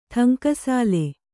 ♪ ṭhaŋkasāle